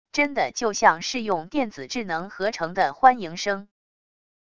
真的就像是用电子智能合成的欢迎声wav音频